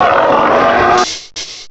pokeemerald / sound / direct_sound_samples / cries / hippowdon.aif